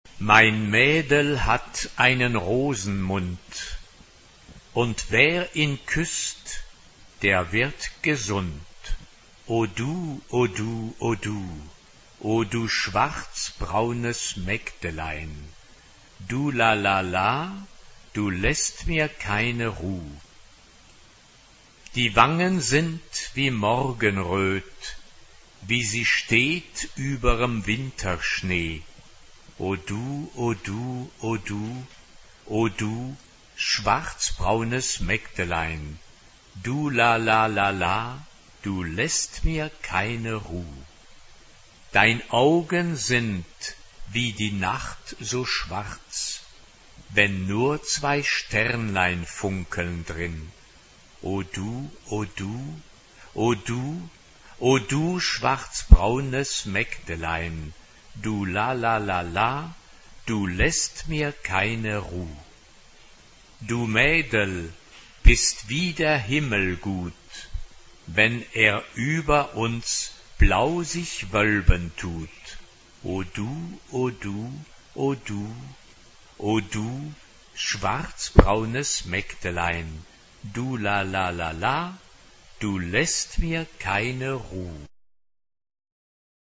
TTBB (4 voix égales d'hommes) ; Partition complète.
Genre-Style-Forme : Chanson ; Profane
Tonalité : do majeur